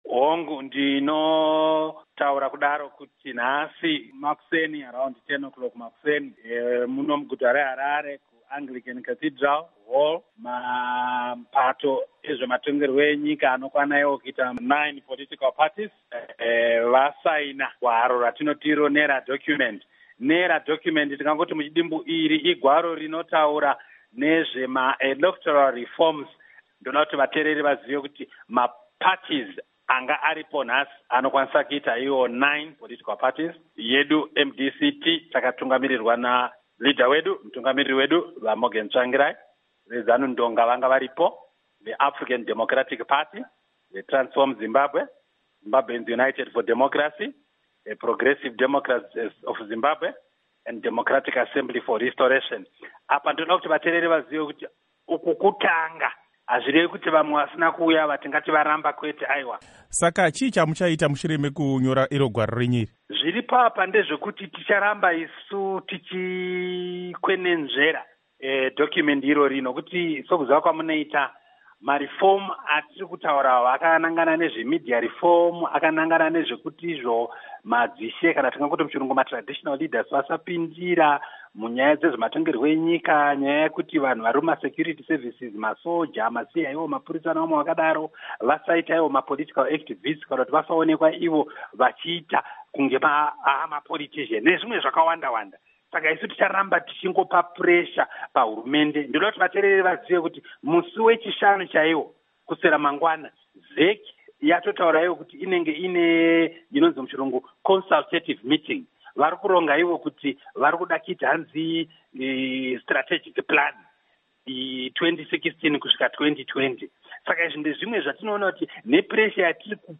Hurukuro naVaObert Gutu